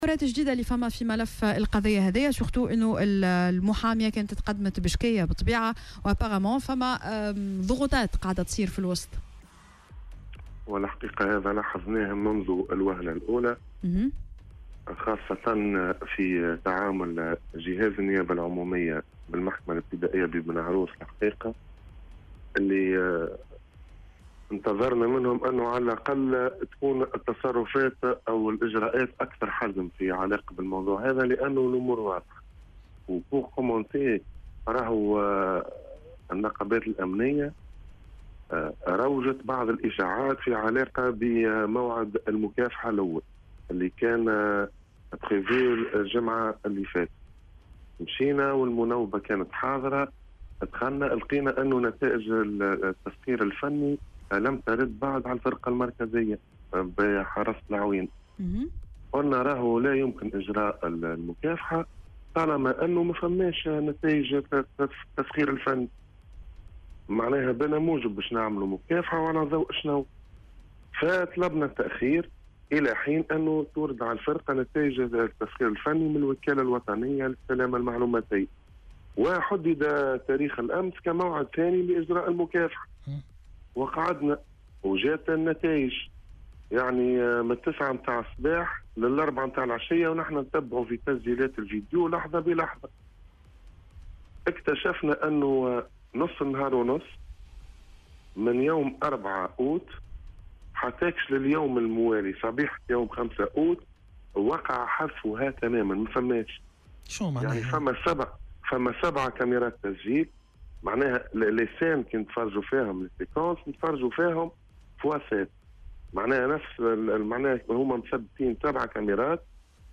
وانتقد في مداخلة له اليوم على "الجوهرة أف أم" تعاطي النيابة العمومية بالمحكمة الابتدائية ببن عروس مع الملف، مشيرا إلى أنهم كانوا ينتظرون منها إجراءات أكثر حزم، وفق قوله.